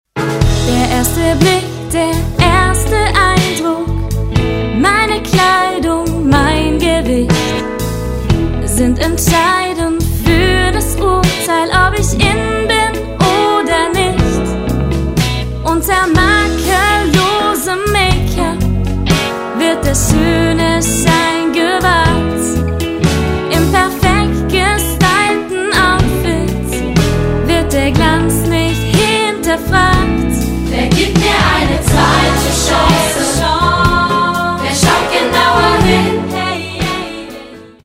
Musical-CD
14 Lieder und kurze Theaterszenen